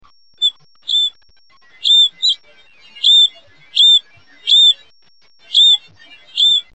a. A soft whit (R,F).
Very similar to that of the Hammond's Flycatcher.